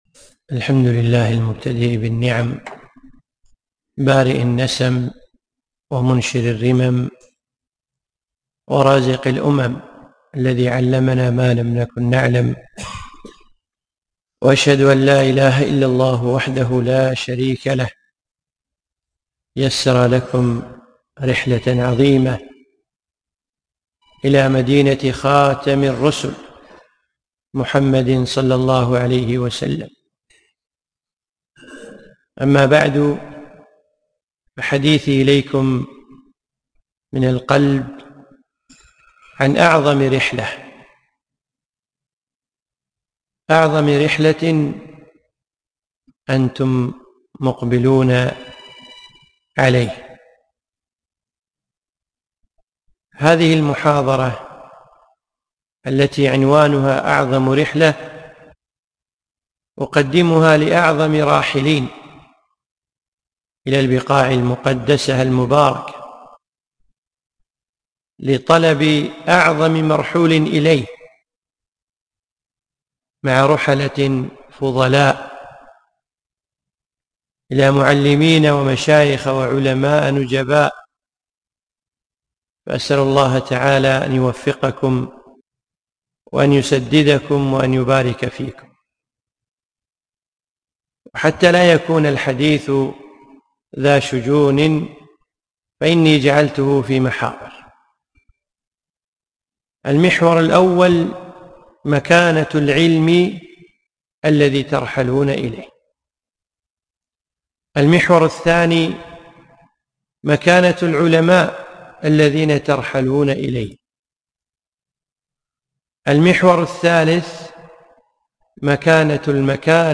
محاضرة - أعظم رحلة ( لطلاب العلم الملتحقين ببرنامج مهمات العلم 1444)